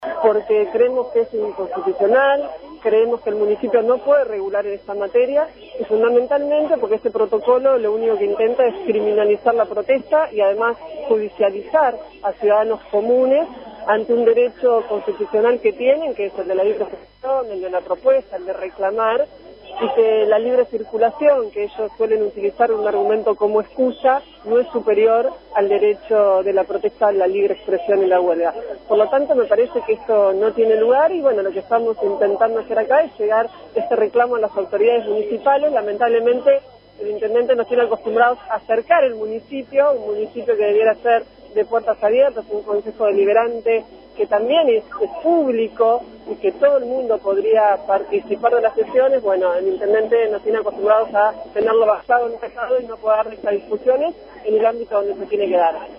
Valeria Amendolara, Diputada Provincial del Frente para la Victoria (FpV), estuvo acompañando la jornada en la puerta del municipio y se manifestó “en absoluto rechazo al proyecto que ingresa hoy al concejo deliberante porque creemos que es inconstitucional; creemos que el municipio no puede regular en esta materia; y fundamentalmente porque éste protocolo lo único que intenta es criminalizar la protesta y además judicializar a ciudadanos comunes”, además aclaró que “la libre circulación, que es el argumento que ellos suelen utilizar como excusa, no es superior al derecho de la protesta y la libre expresión”.